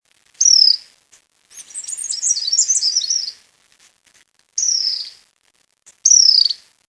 Troglodytes ochraceus (ochraceous wren)
Troglodytes ochraceus: sounds (1)
The song is completely different.